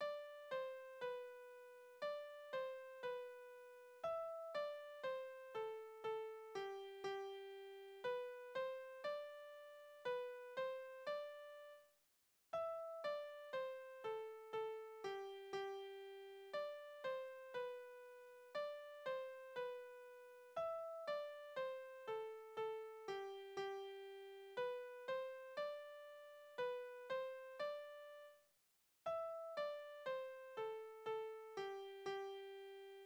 Kindertänze: Die bunte Schürze
Tonart: G-Dur
Taktart: 2/4
Tonumfang: große Sexte